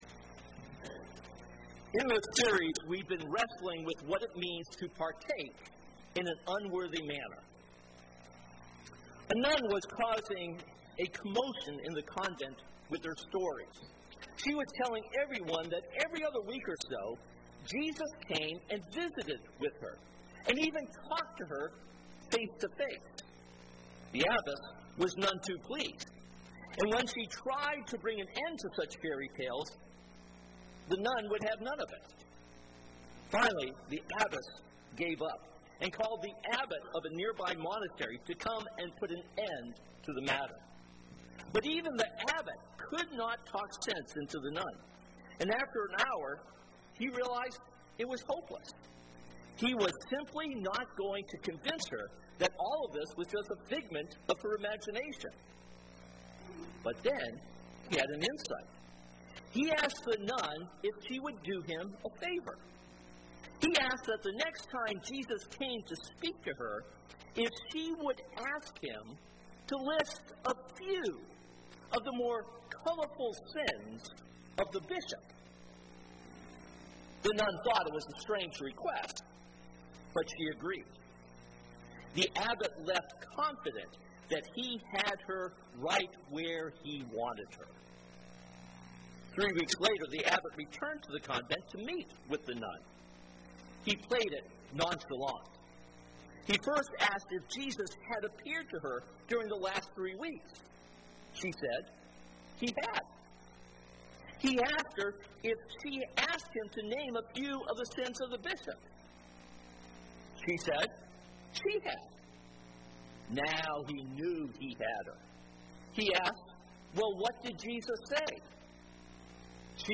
This sermon is based on 1 Corinthians 11:23-34.